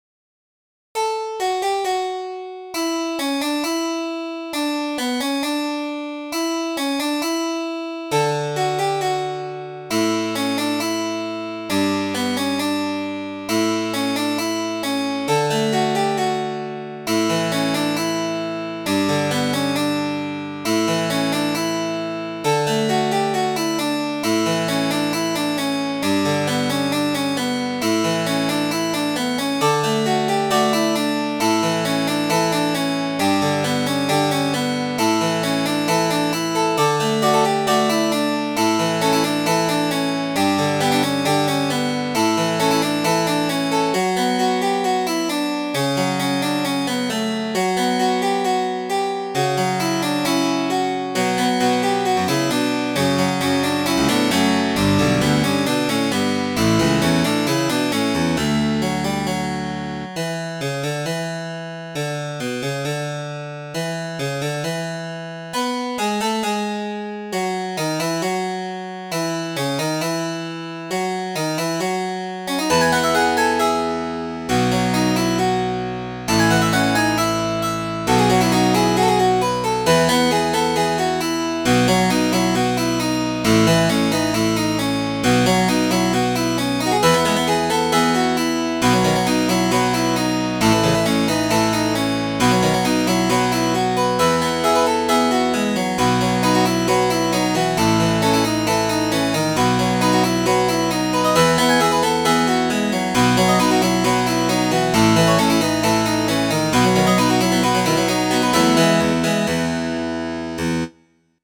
This is another "classical-lite" original, featuring a 1-5-4-5 progression. It doesn't have the complexity of classical or baroque, though.